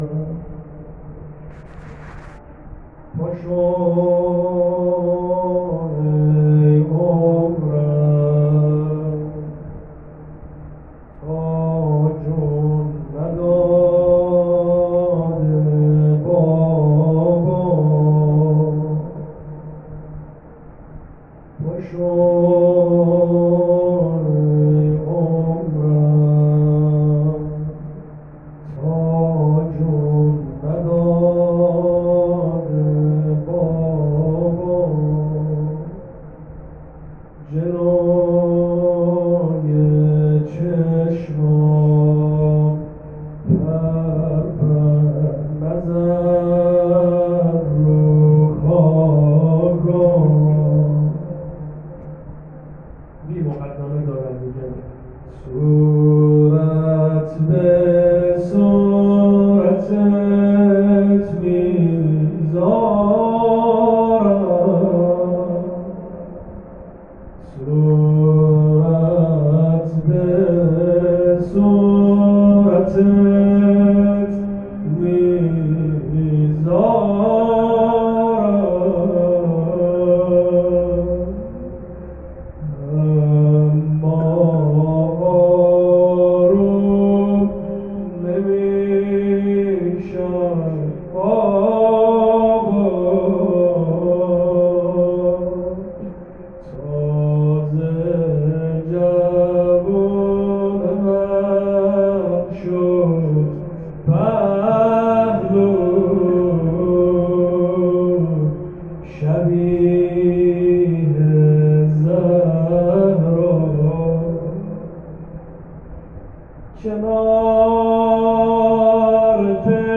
روضه شب هشتم